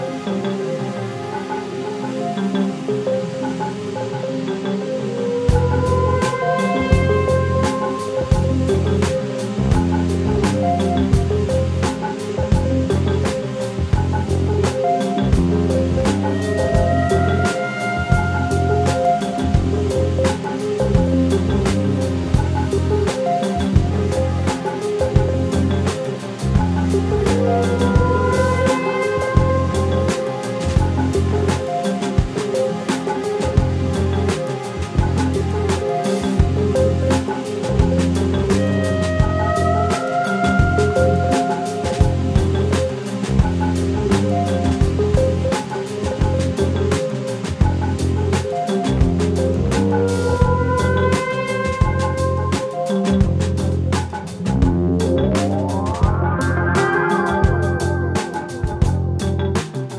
今回は、スイスの高地にあり、高級スキーリゾート地として知られる村レザンで6日間かけて録音したという作品。
清涼感や浮遊感を纏ったクールな現代ジャズ/フュージョンを繰り広げています。